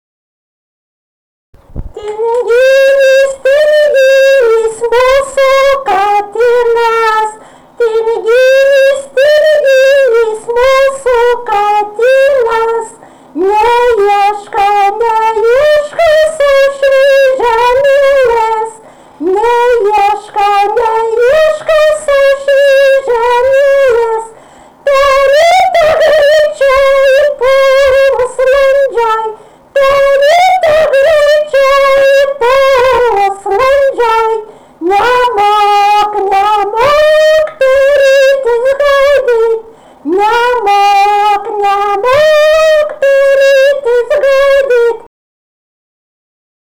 daina, žaidimai ir rateliai
vokalinis